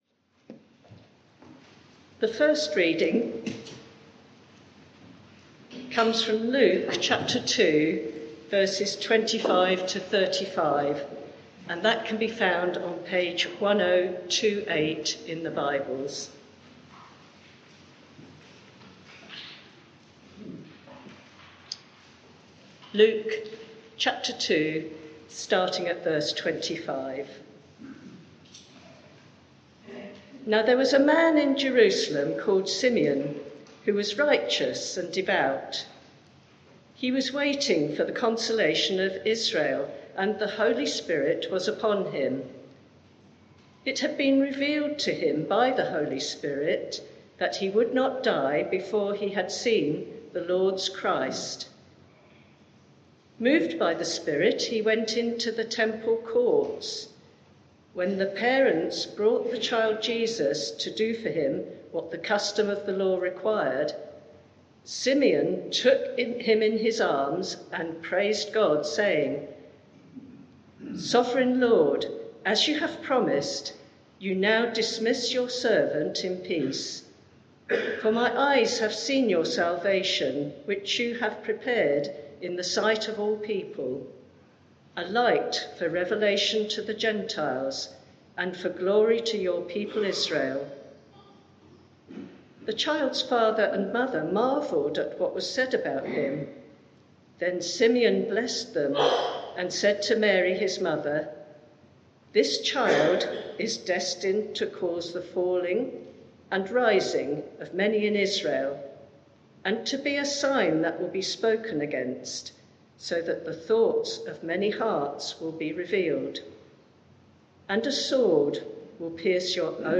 Media for 11am Service on Sun 13th Mar 2022 11:00 Speaker
Series: The Servant King Theme: A Light to the Nations Sermon (audio)